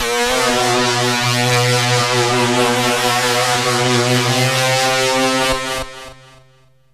SYNTH LEADS-1 0013.wav